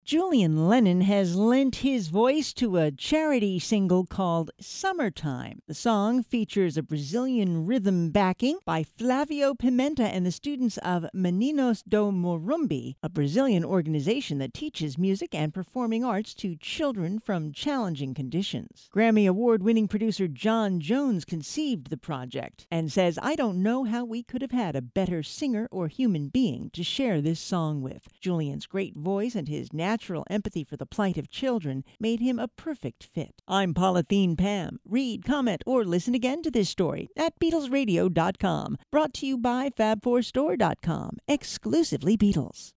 poignant vocals
hypnotic Brazilian rhythm
additional percussion
who is featured on bass, guitars and keyboards